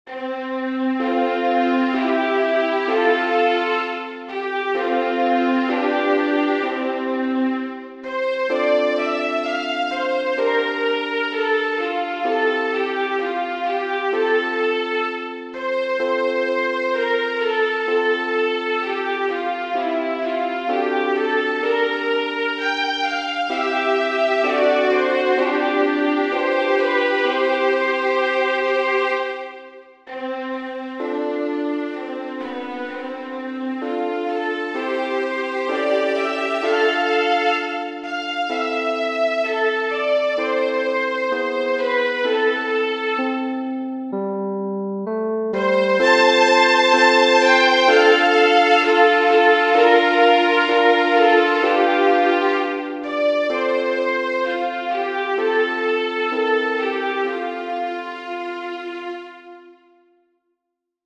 Sveinbjornsson, S. Genere: Sociali e Patriottiche Parole di Matthìas Jochumsson Testo originale islandese Ó, guð vors lands!